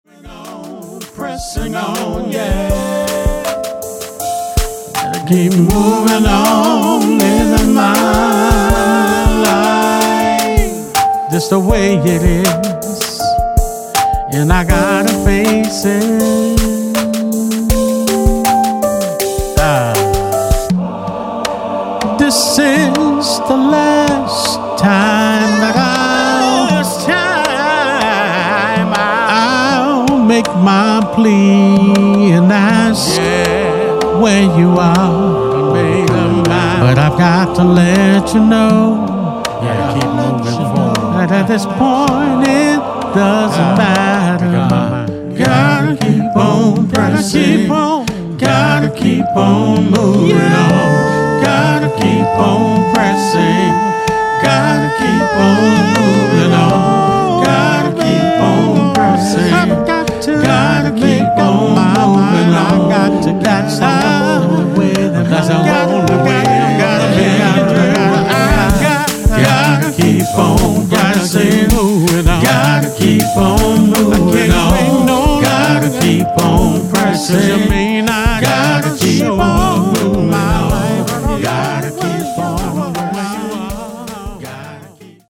RHYTHMIC SOUL